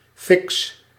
Ääntäminen
France (Paris): IPA: /fɔʁ/